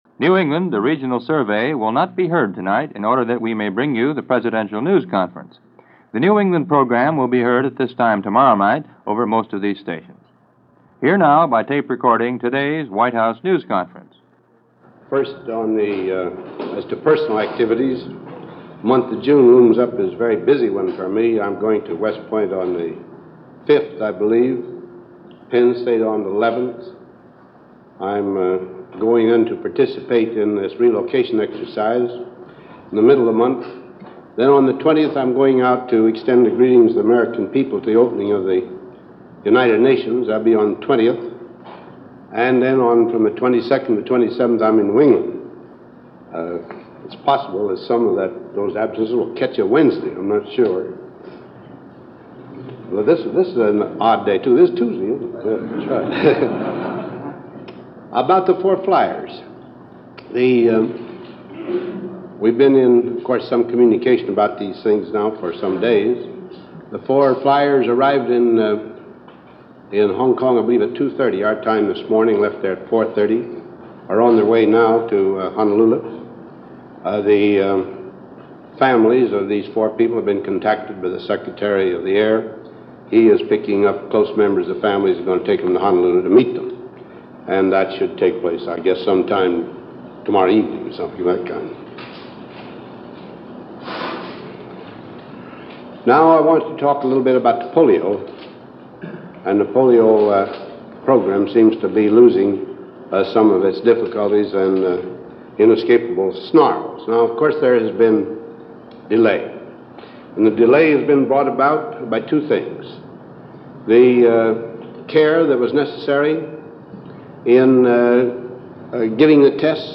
An Eisenhower press Conference - May 31, 1955 - President Eisenhower holds his regular news conference, discussing several important issues of the day.